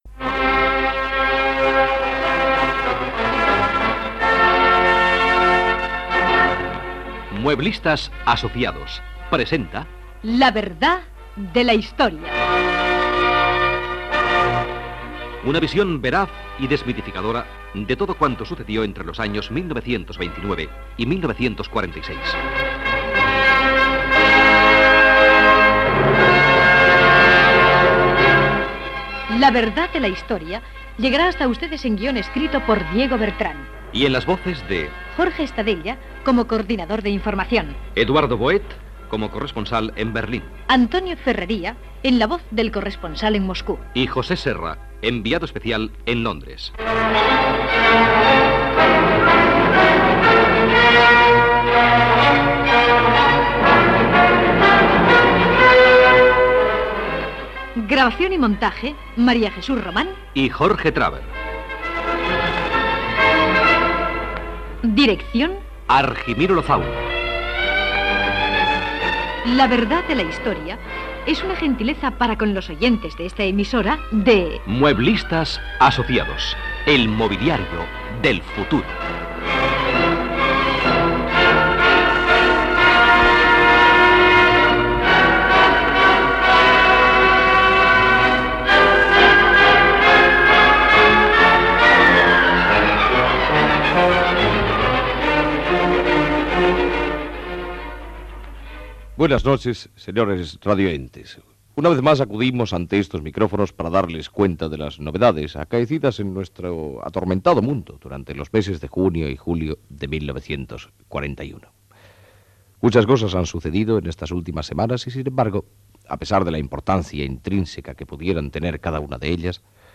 Careta del programa, amb els noms de l'equip, espai dedicat a recrear fets passats els mesos de juny i juliol de l'any 1941